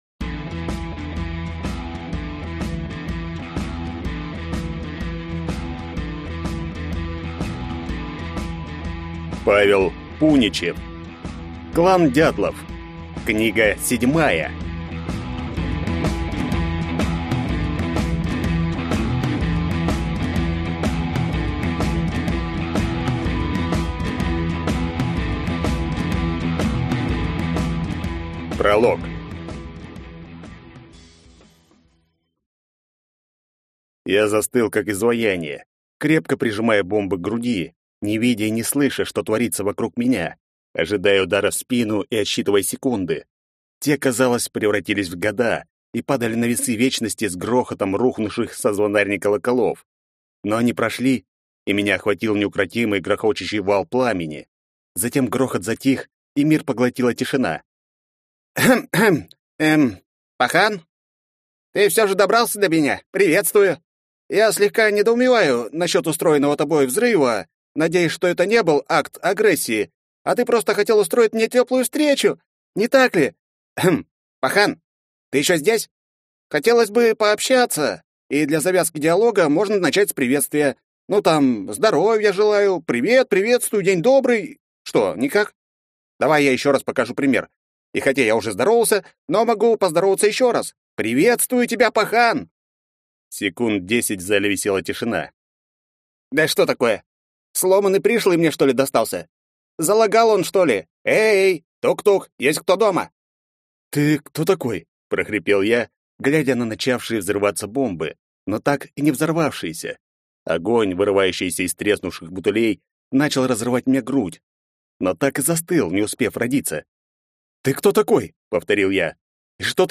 Аудиокнига Клан «Дятлов». Книга 7 | Библиотека аудиокниг